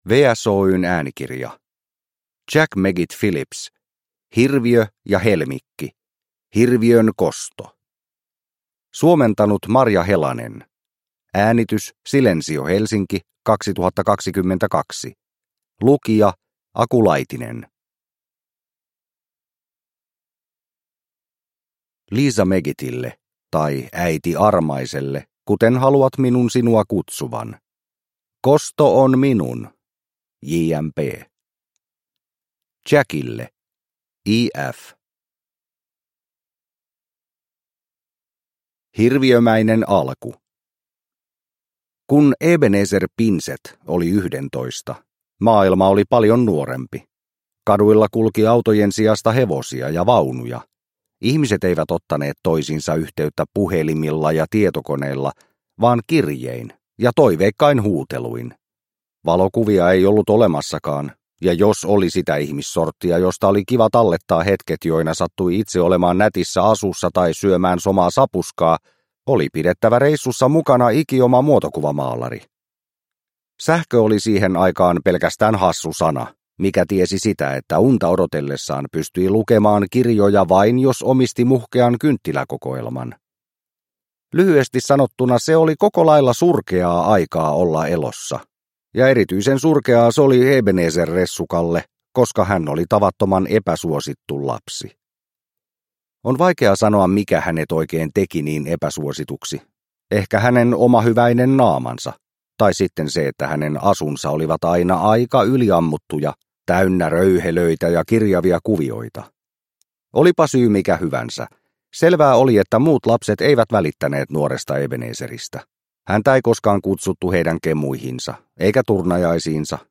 Hirviön kosto – Ljudbok – Laddas ner